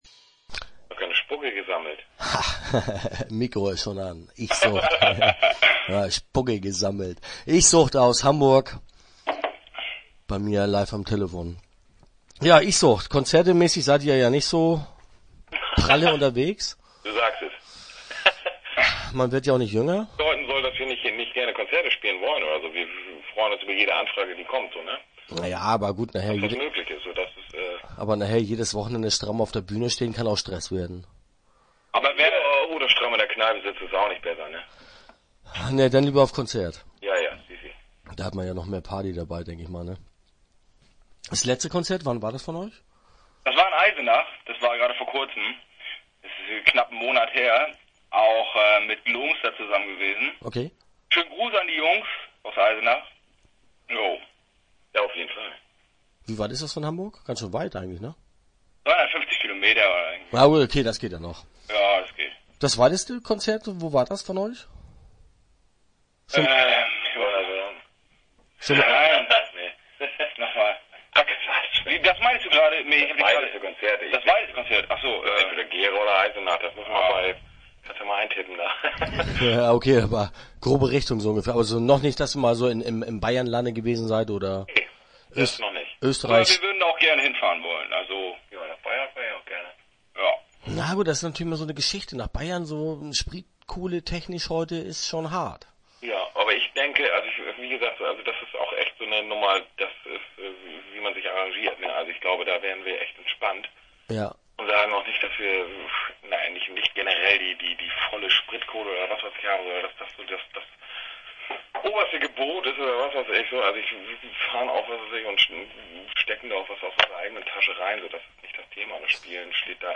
Start » Interviews » IchSucht